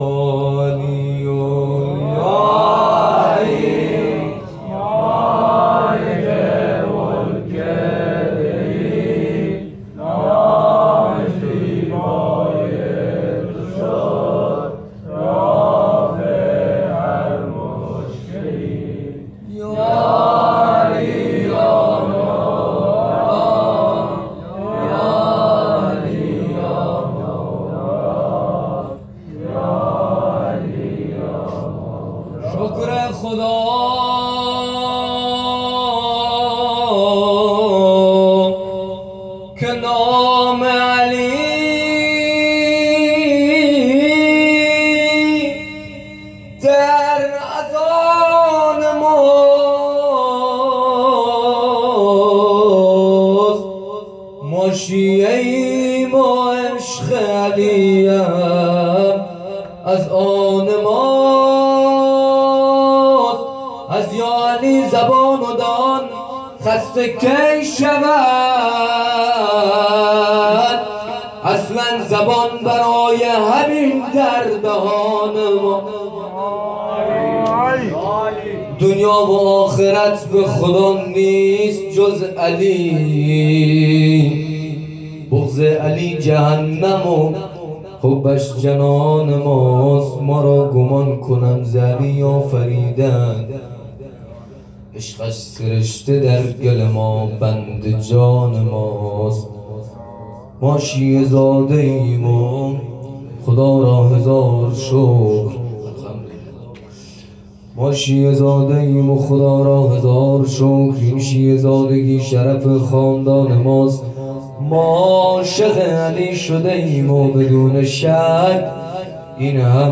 شب اول محرم ۹۷ هیئت صادقیون(ع)
11-زمزمه-ومدح-امیرالمومنین.mp3